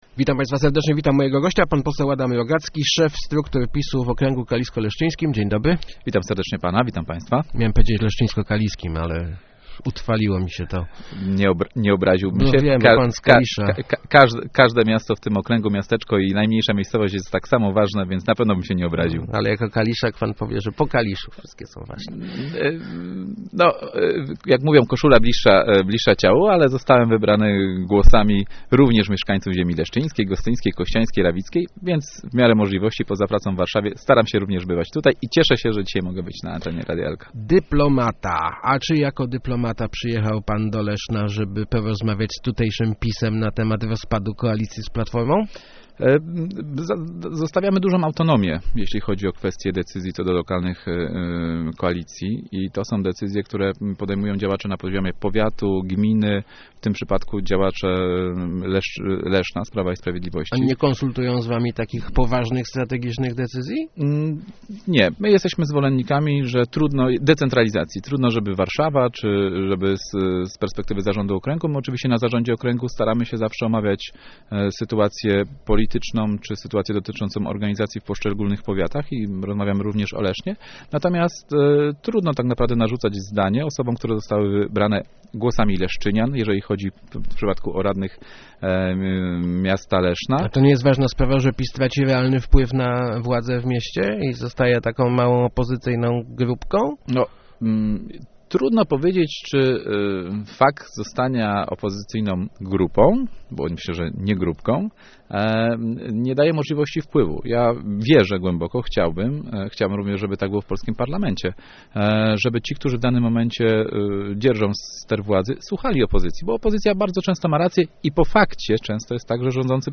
Zapraszam pana premiera, żeby leszczyniacy mogli zapytać go o niezrealizowane obietnice - mówił w Rozmowach Elki poseł Adam Rogacki (PiS).